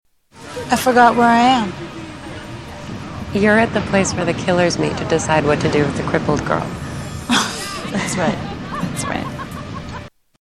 Tags: Movie Mystery the happening movie clips M. Night Shyamalan the happening